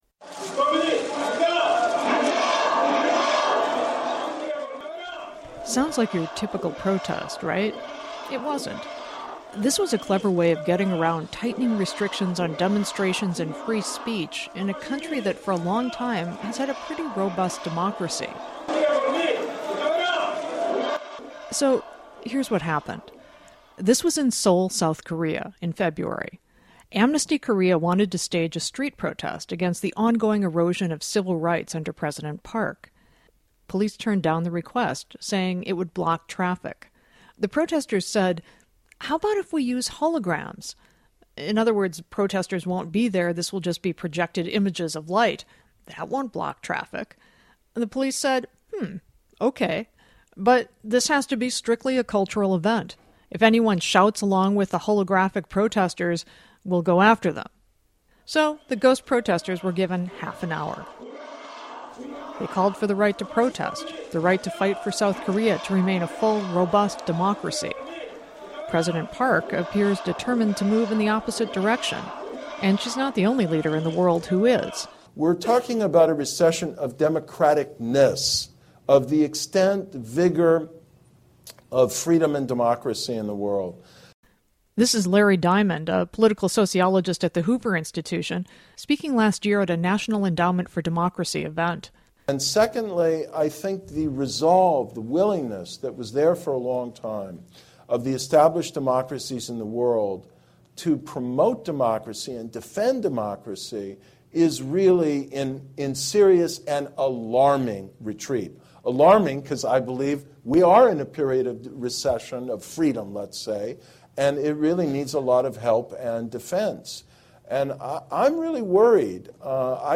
Listen in and challenge your assumptions, with two guys who study this for a living